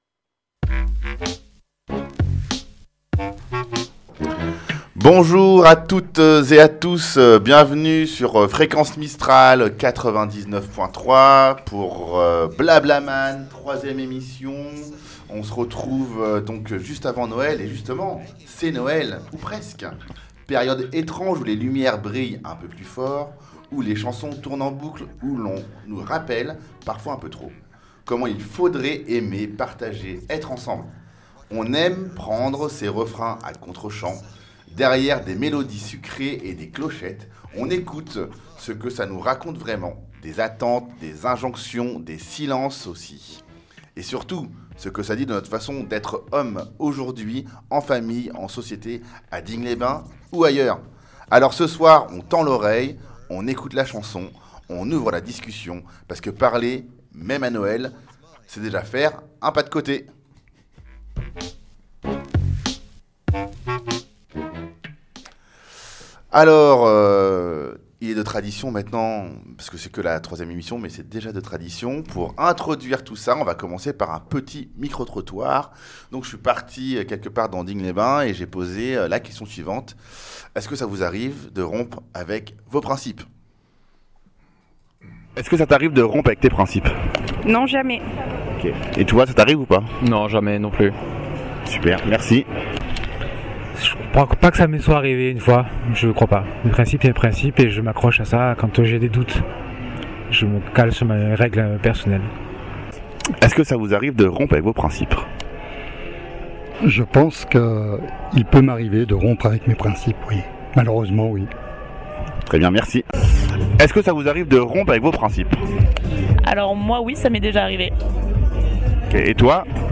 Mardi 23 Décembre 2025 BlaBlaMan, c’est le talk qui donne la parole aux hommes pour évoquer les transformations liées aux ruptures que nous traversons dans la vie.